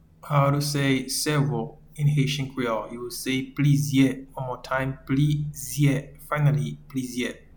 Pronunciation and Transcript:
Several-in-Haitian-Creole-Plizye.mp3